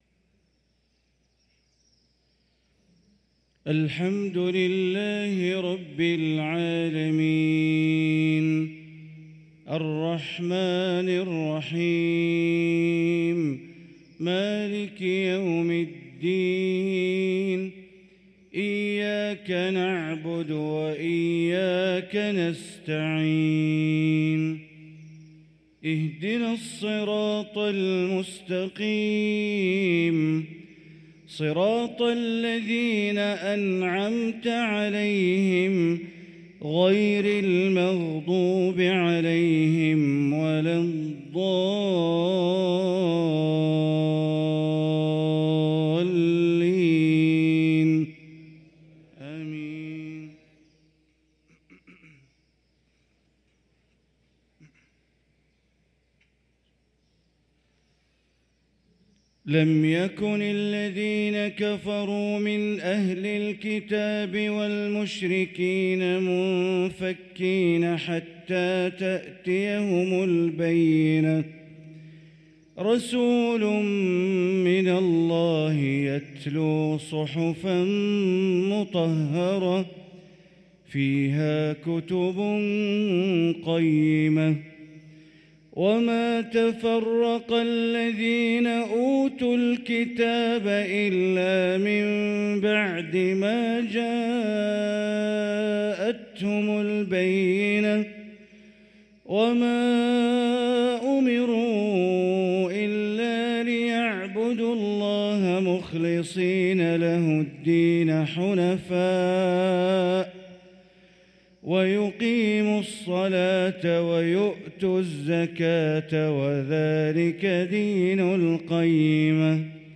صلاة المغرب للقارئ بندر بليلة 27 جمادي الآخر 1445 هـ
تِلَاوَات الْحَرَمَيْن .